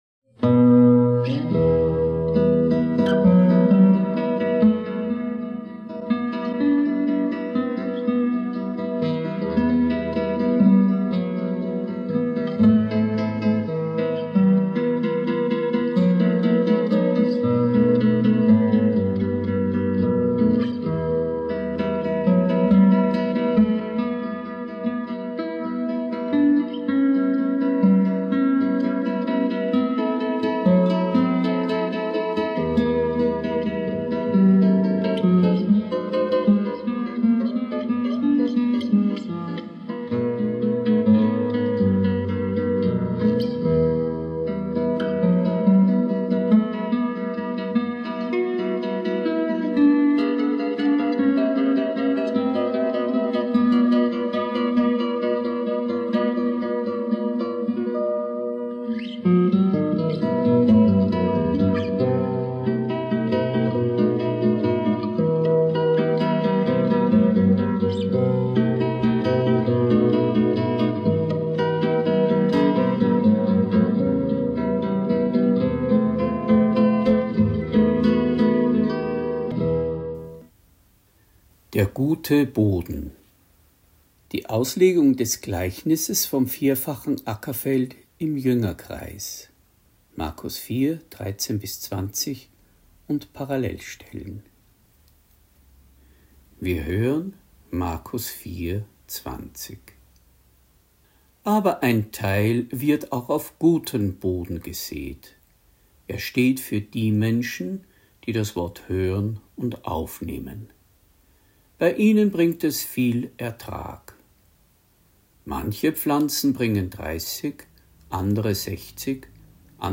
Predigt | NT02 Markus 4,13-20 Das 4-fache Ackerfeld (5) Guter Boden